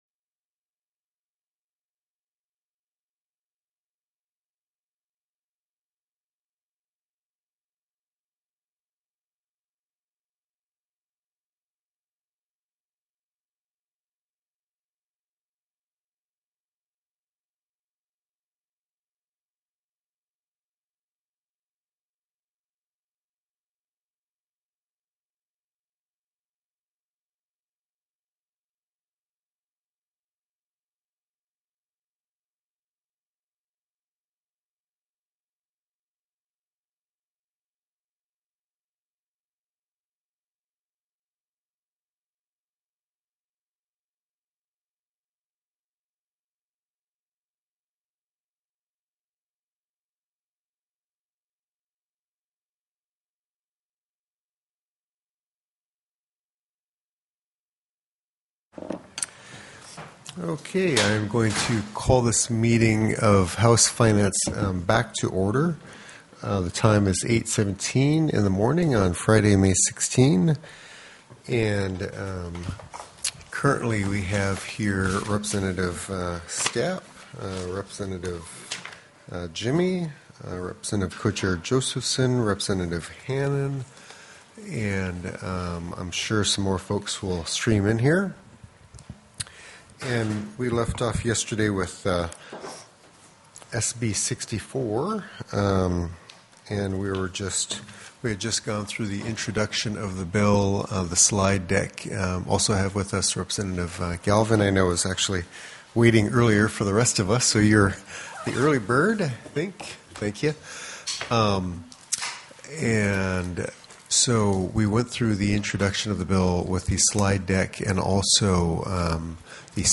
PRESENT VIA TELECONFERENCE Carol Beecher, Director, Division of Elections.